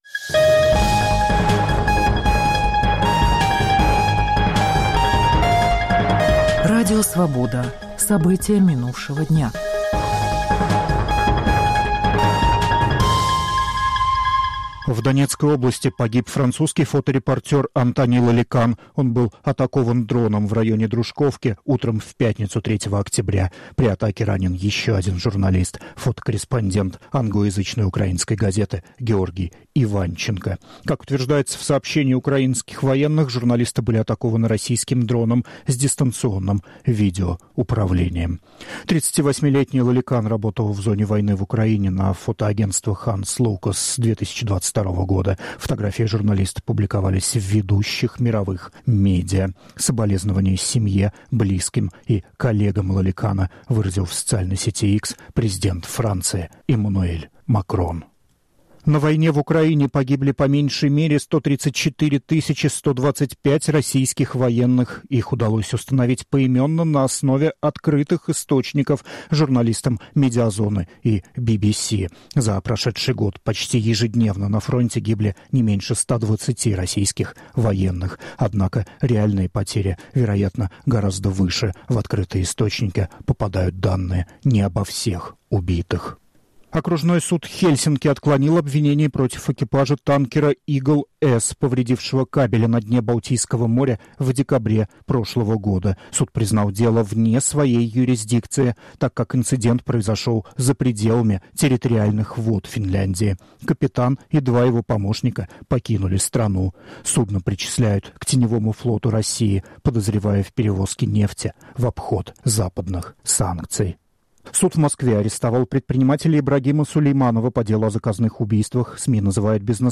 Аудионовости